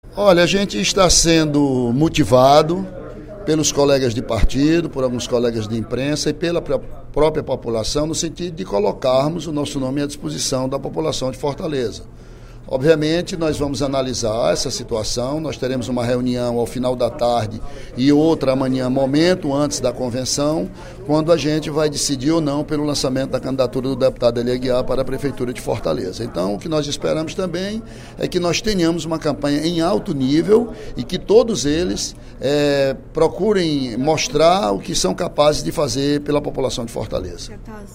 O deputado Ely Aguiar (PSDC) afirmou, nesta quarta-feira (03/08), durante o primeiro expediente da sessão plenária, estar surpreso e “extremamente entusiasmado”, com a possibilidade de disputar as eleições municipais em Fortaleza.